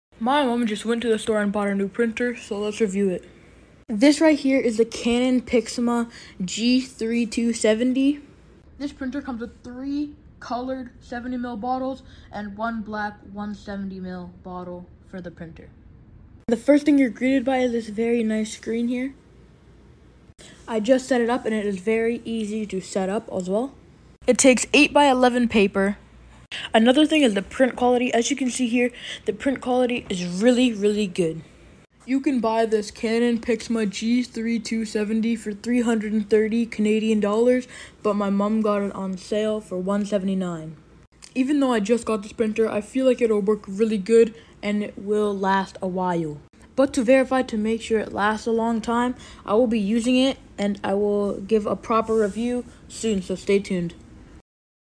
New canon pixma g3270 printer sound effects free download